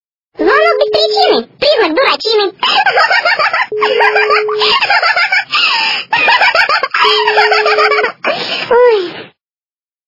» Звуки » Смешные » Обкуренный голос - Звонок без причины-признак дурачины...
При прослушивании Обкуренный голос - Звонок без причины-признак дурачины... качество понижено и присутствуют гудки.